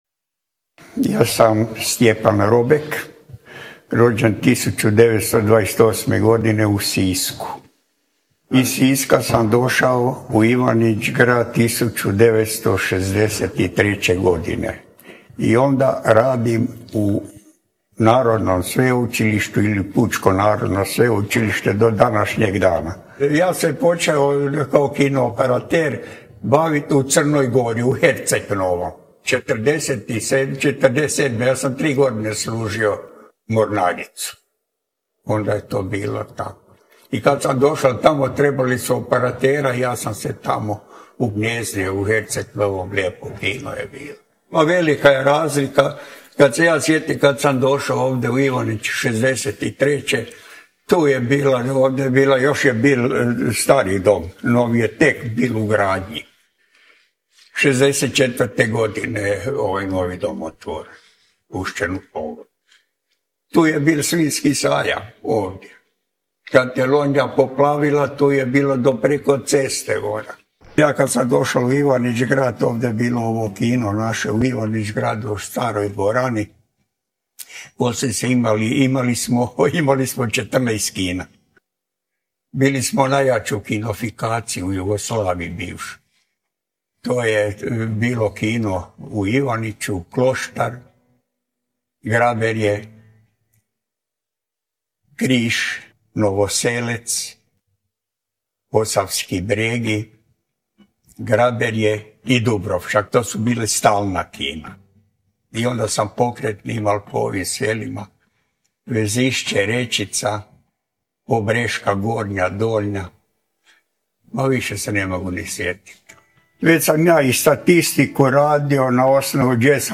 Uz tekst objavljujemo i originalni audio zapis razgovora, snimljen 2013. godine i sačuvan u arhivi portala Volim Ivanić.
Originalni audio zapis razgovora